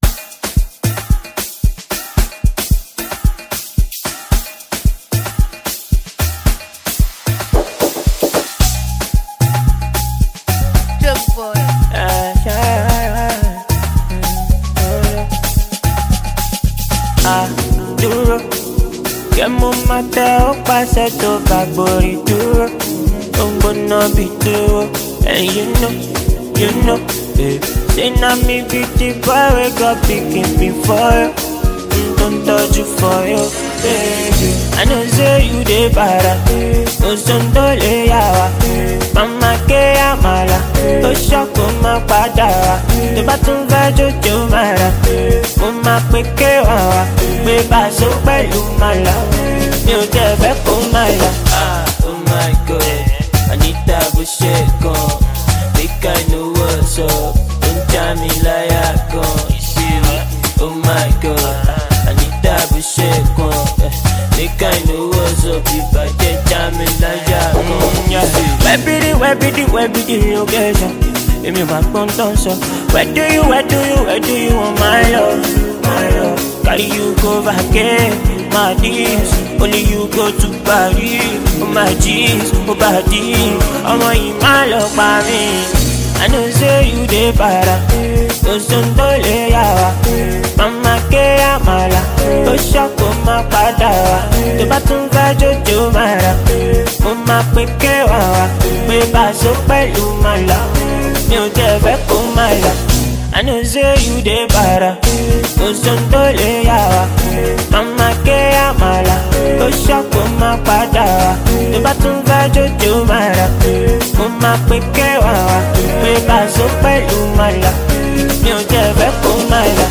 Nigerian singer
Afrobeats